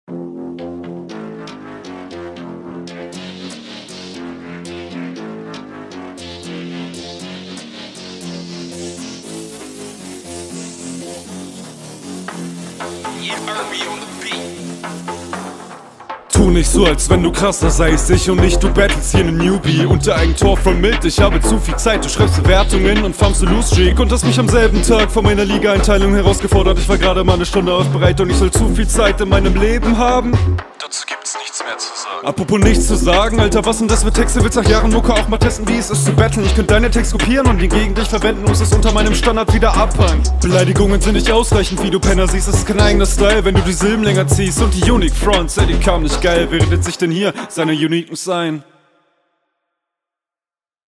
Der Beat steht dir schon sehr viel besser als deinem Gegner; dadurch dass du nicht …
Flow: Der Flow ist echt super cool.